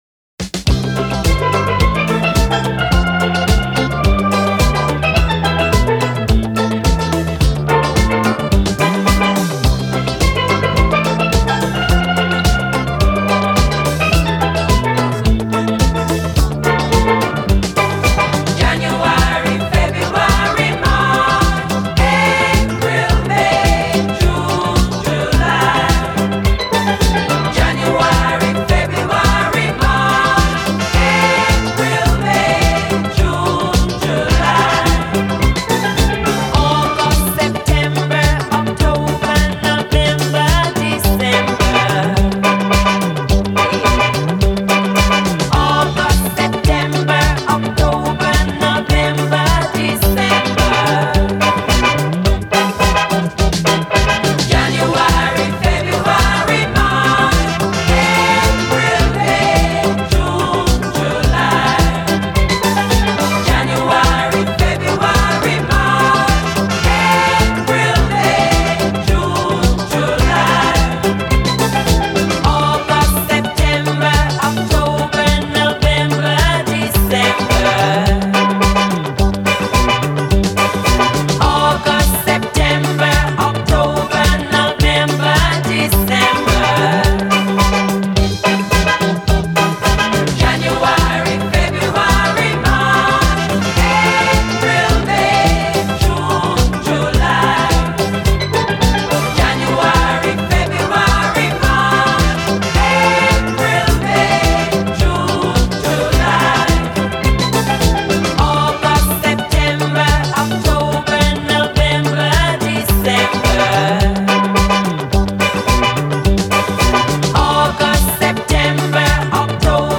Educational disco.